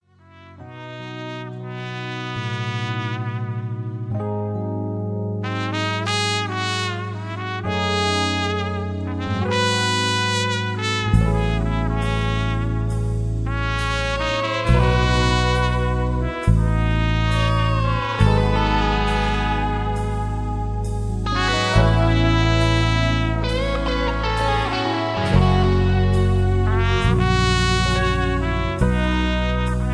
british rock bands